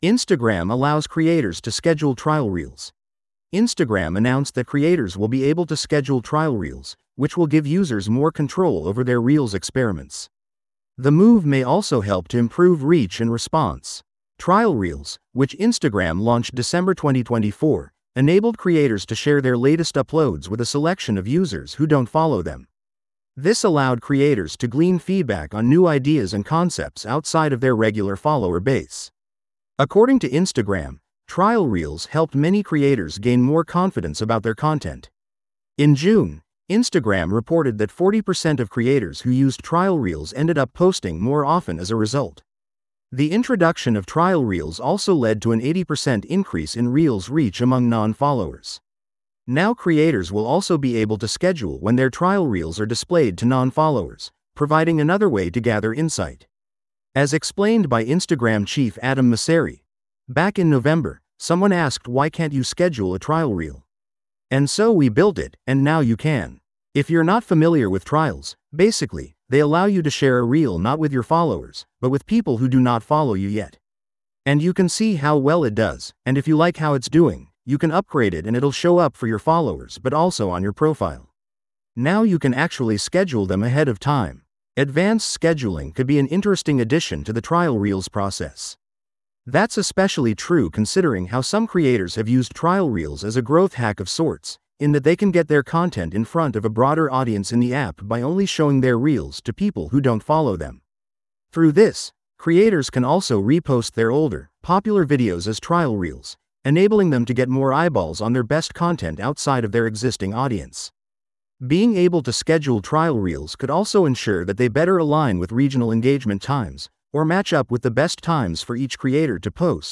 This audio is auto-generated. Please let us know if you have feedback.